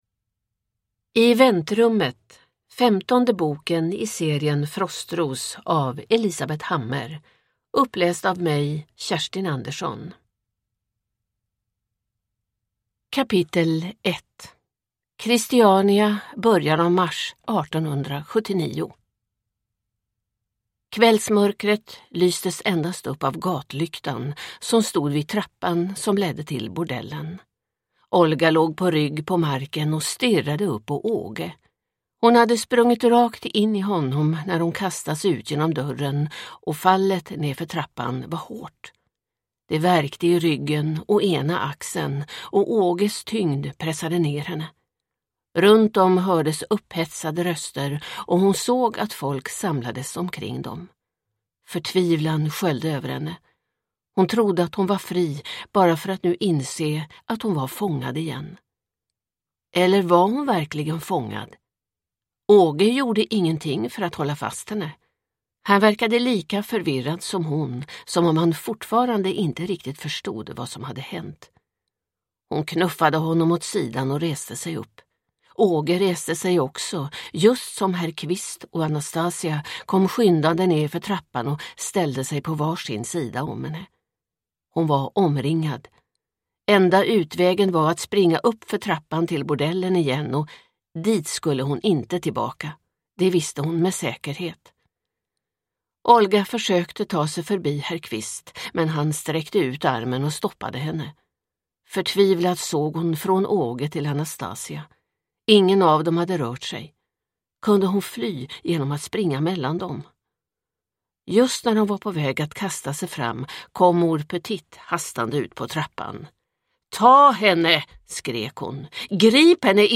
I väntrummet – Ljudbok